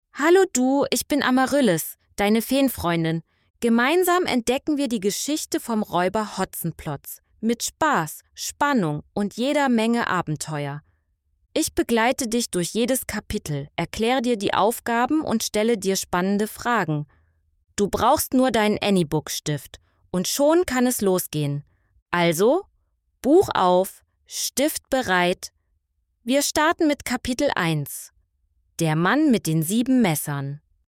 Inklusive Hörunterstützung mit dem Anybook Pro
Alle Aufgaben lassen sich mithilfe kleiner Audio-Codes auch anhören – vorgelesen von der Fee Amaryllis.